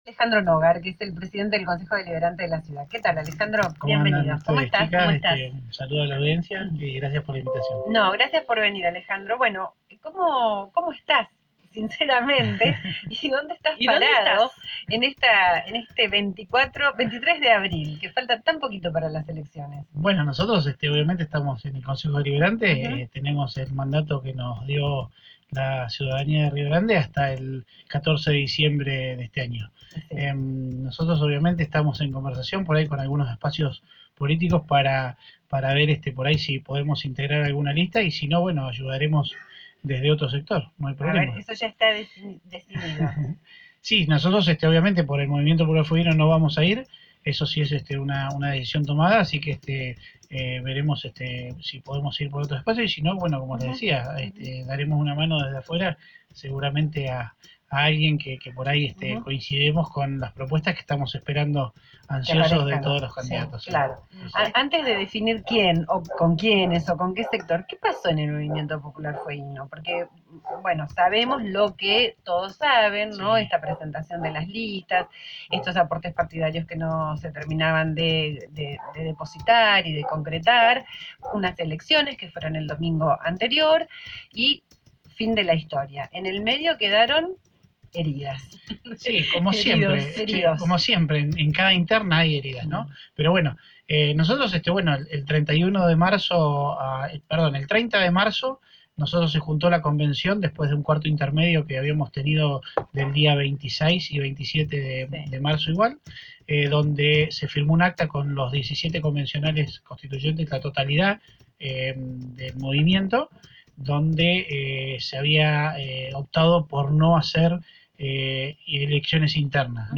Mart 23/04/19.- El presidente del concejo deliberante de Rio Grande pasó por el programa Tarde pero Seguro y se refirió a la situación del MPF, su participación en alguna lista que aún no está definido y lanzó críticas para distintos sectores internos.